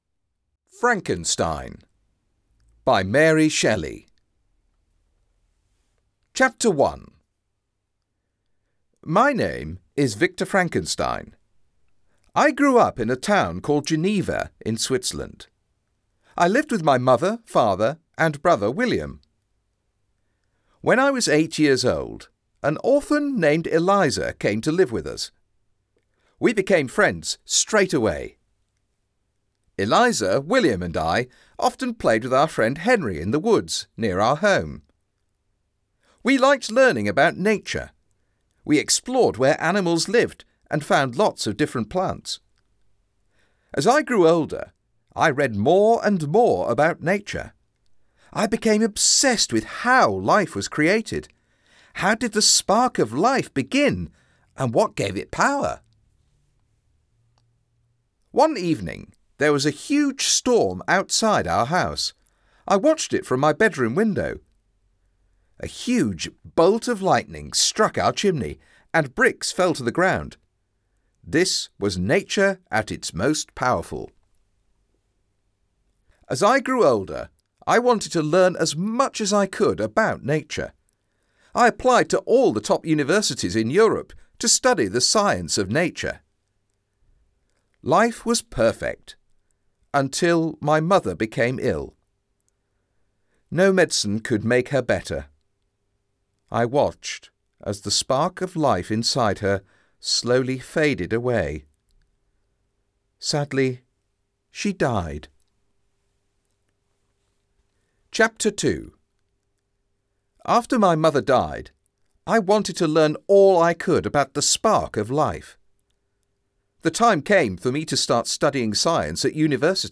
Frankenstein (Easier) Audiobook